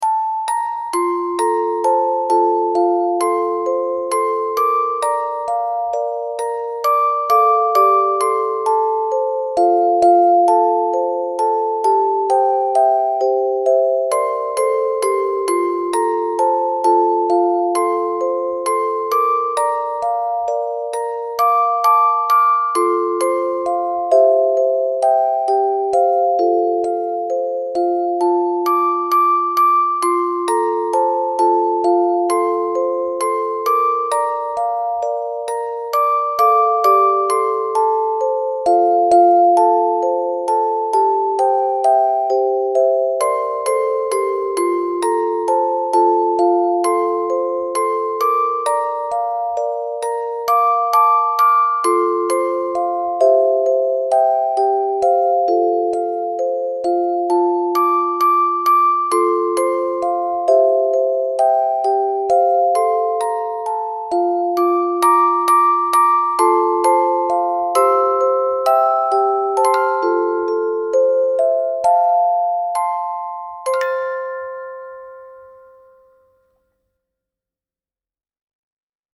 私の心の中に、長いこと眠っていたメロディーをオルゴールに。
気持ちの落ち込んでいる時に少し前向きになれそうな癒しの曲ですね(^-^)